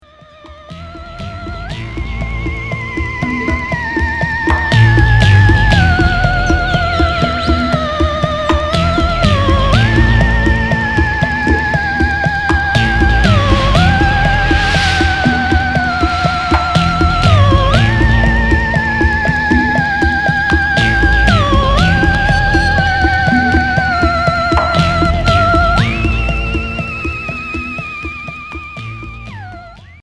Expérimental synthétique Unique 45t retour à l'accueil